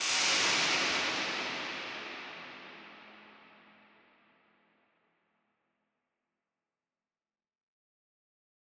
Index of /musicradar/shimmer-and-sparkle-samples/Filtered Noise Hits
SaS_NoiseFilterA-06.wav